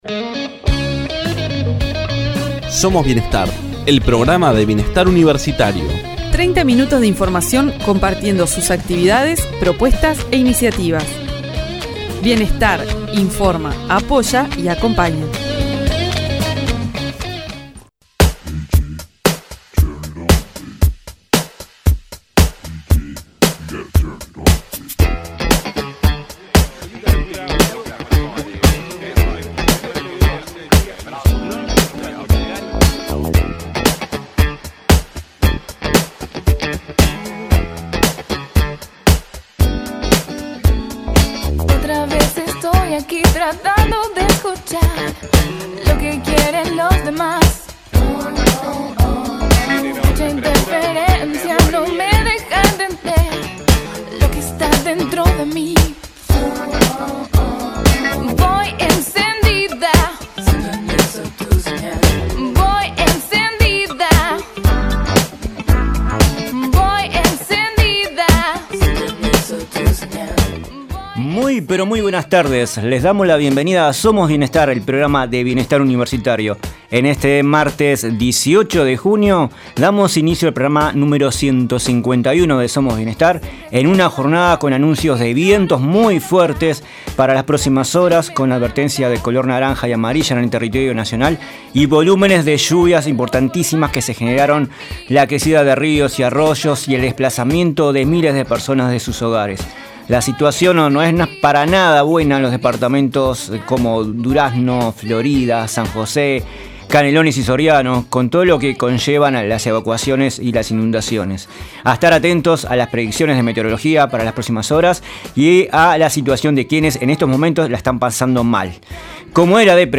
La Intendencia de Canelones inaugurará en agosto su hogar estudiantil -el primero que tendrá la comuna canaria- con el objetivo de hospedar a jóvenes del departamento que se encuentran realizando sus estudios terciarios en instituciones educativas públicas de Montevideo. La la Directora General de Desarrollo Humano de la Intendencia de Canelones, Gabriela Garrido explicó a Somos Bienestar que con el Hogar Municipal la finalidad es apoyar y brindar la oportunidad de continuar con los estudios de enseñanza superior.
Visitaron el programa jugadoras del equipo mixto de voleibol PsicoFIC que participa del primer campeonato interfacultades.